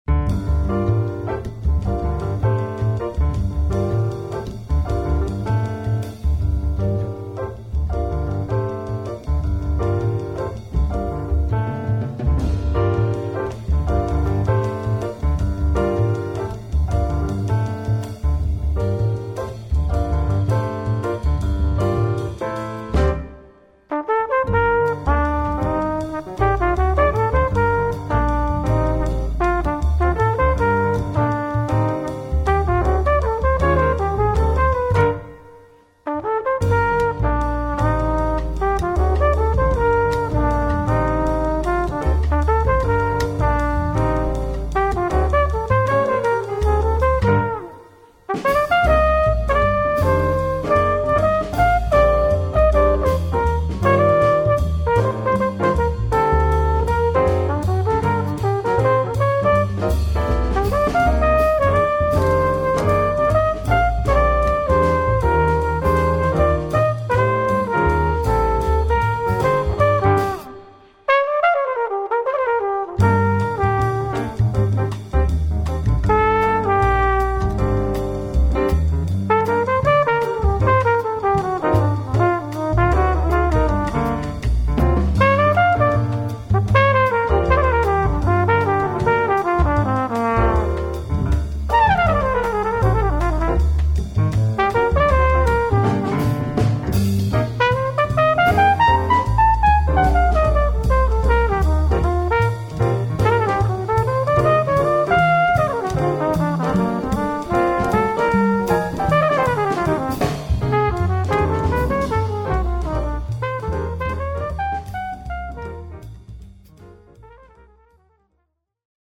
piano
tenor sax
trumpet & flügelhorn
tenor sax & flute
bass
drums